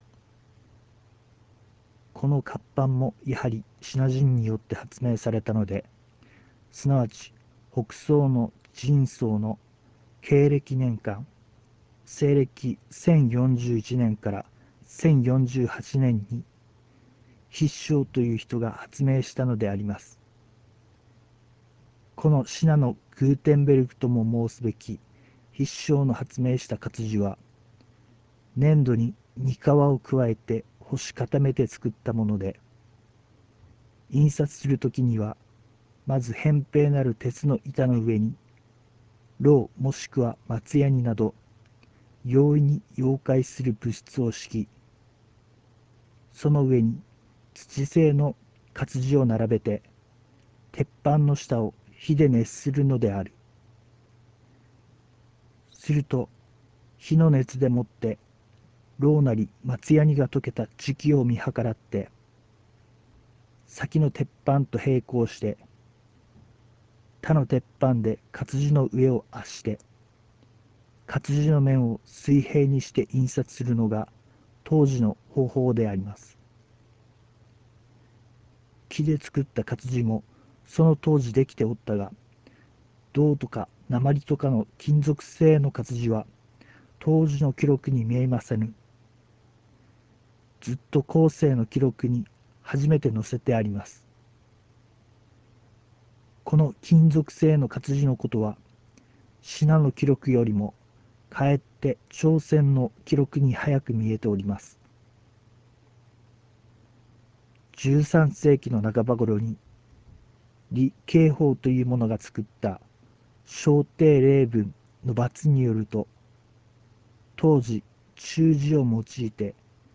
（朗読：RealMedia 形式　308KB、2'28''）